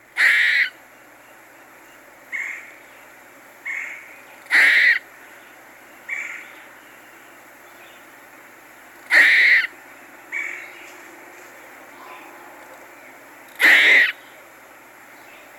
The squirrels answered back my request to be careful with their pine cones with a bird-like
squawking sound.
squierrl.mp3